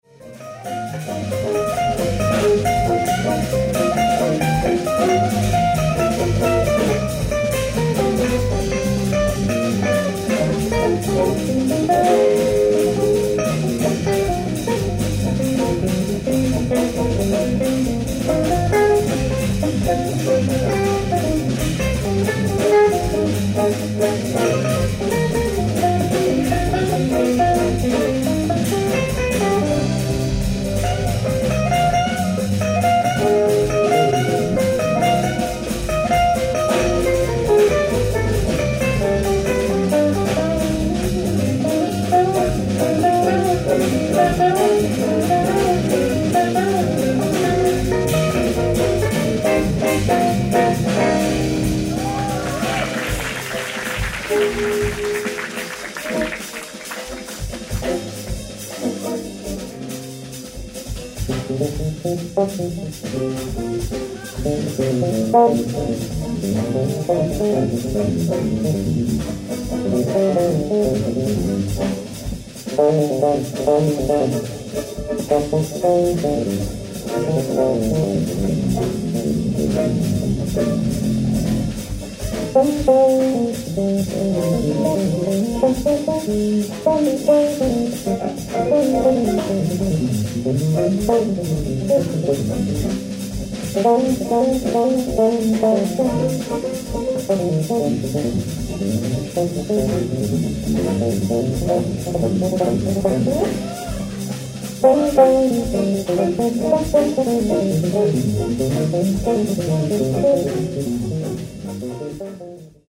超極上オーディエンス録音！！
※試聴用に実際より音質を落としています。